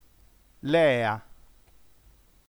lea nf lè.a - ['lɛa] ◊